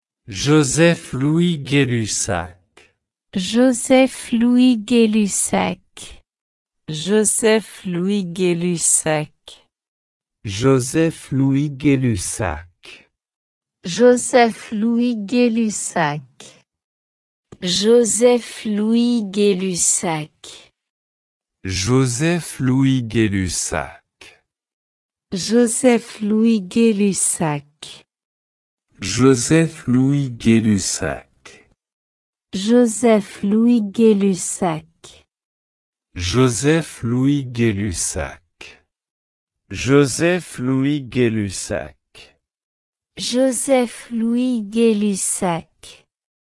Dieser Zusammenhang wird nach Joseph Louis Gay-Lussac (gesprochen „Geh-Lüsak“
Pronunciation_Joseph_Louis_Gay-Lussac.ogg